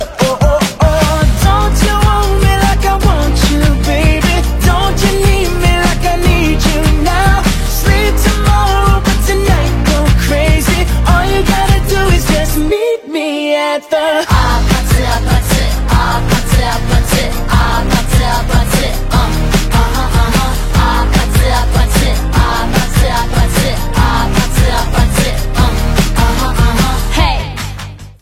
Танцевальные рингтоны
поп